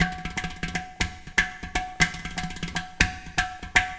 It features a mixture of field and studio recordings and programming for
Tabla,
Oriental Percussion,